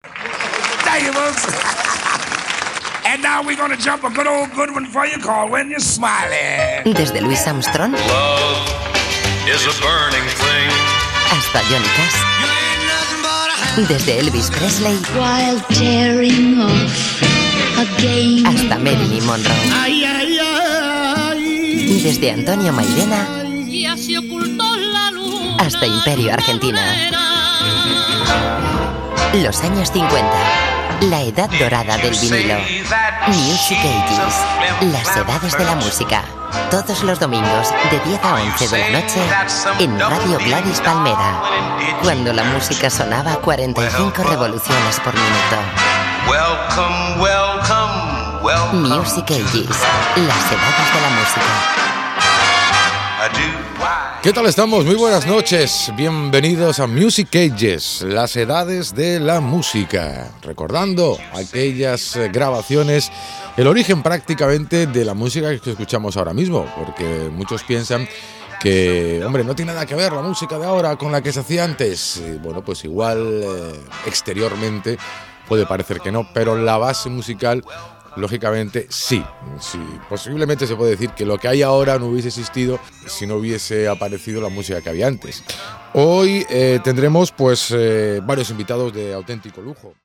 Careta del programa i presentació inicial.
Musical